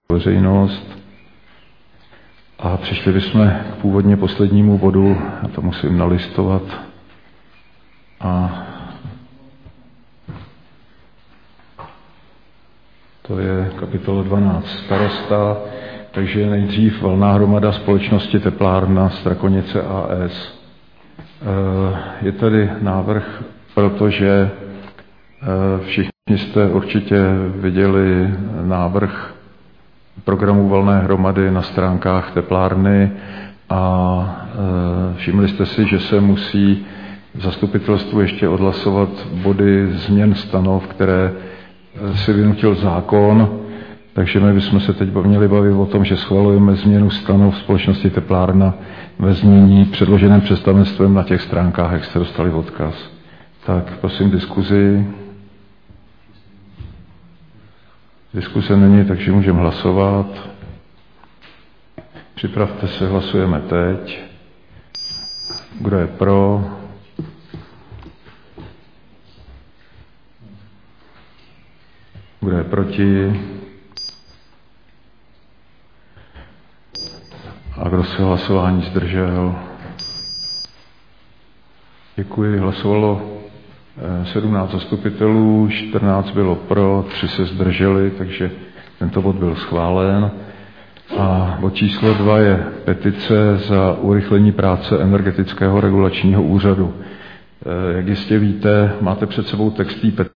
Usnesení Zastupitelstva č.12 ze dne 23.Červen 2021
Záznam jednání: